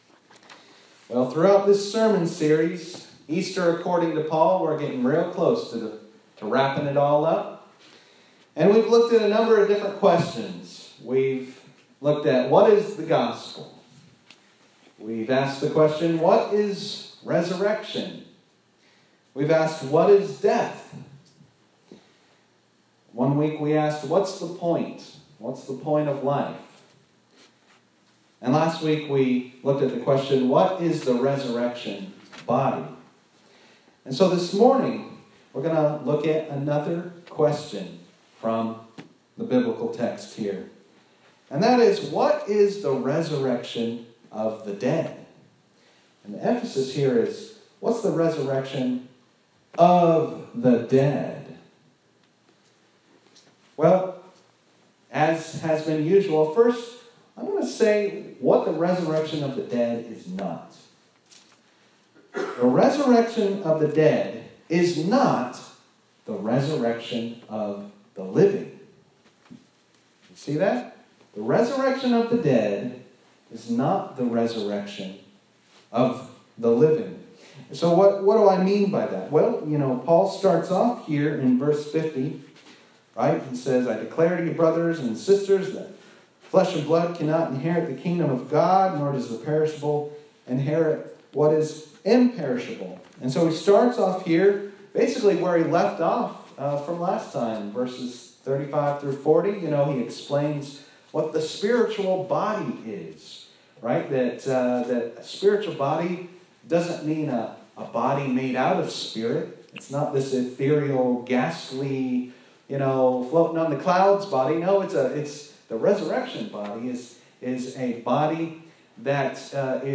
This is Part 6 of a 7-Part sermon series titled “Easter according to Paul” preached at Mt. Gilead UMC in Georgetown, KY.